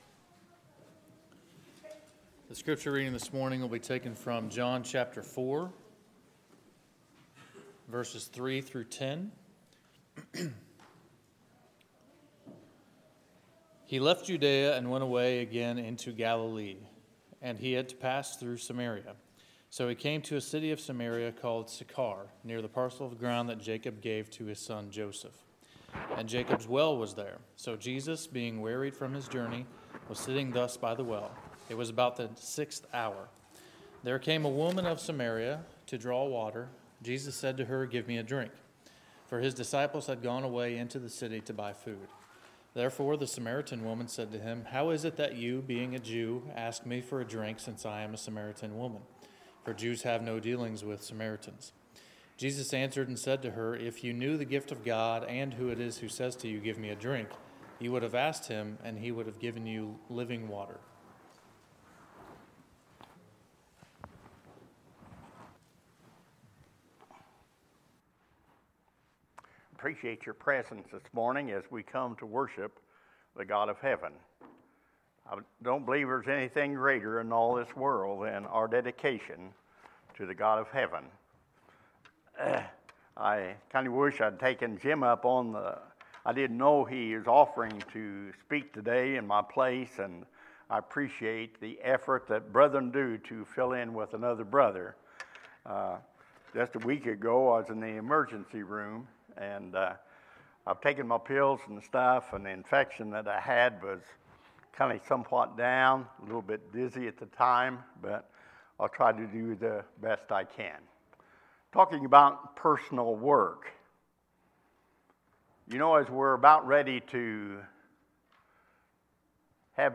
Sermons, December 29, 2019